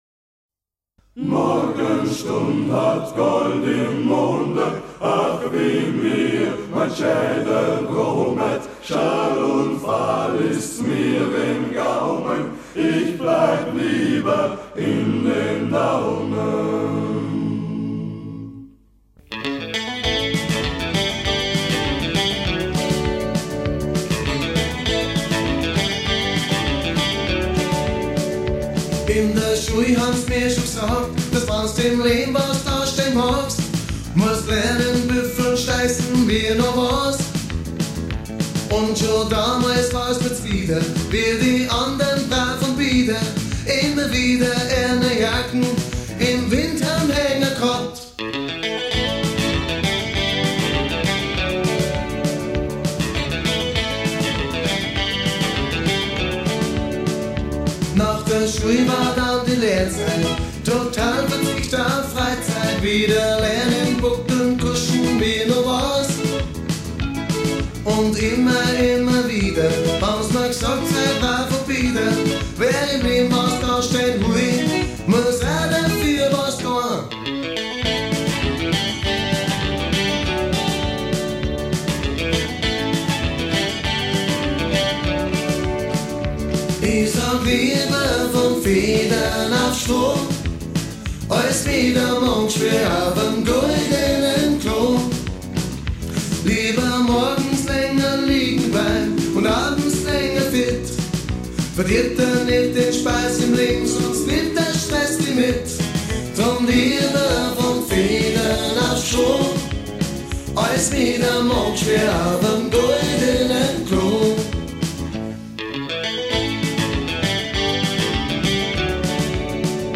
Eine Dialekt Produktion aus 1983
leichter bayerischer Schlager Pop mit Countryakzent.